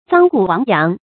臧谷亡羊 注音： ㄗㄤ ㄍㄨˇ ㄨㄤˊ ㄧㄤˊ 讀音讀法： 意思解釋： 《莊子·駢拇》載，臧、谷二人牧羊，臧挾策讀書，谷博塞以游，皆亡其羊。